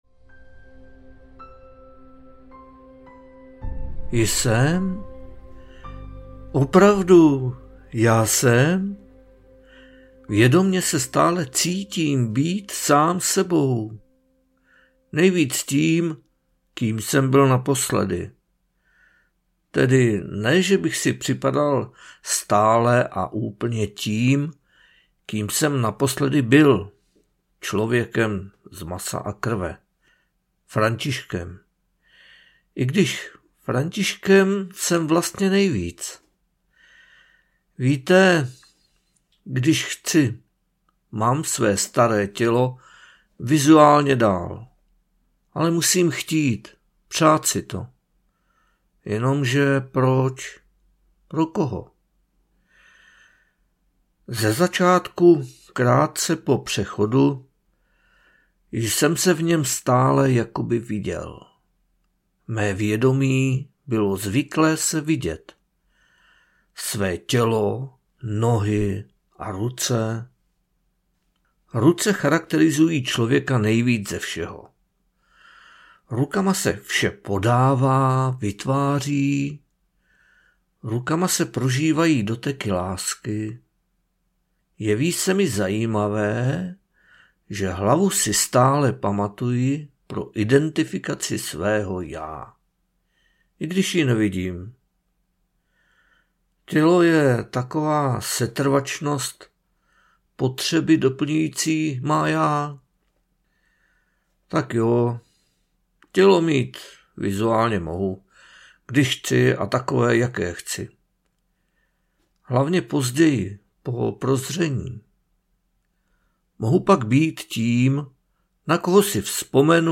Svět Mentogritů audiokniha
Ukázka z knihy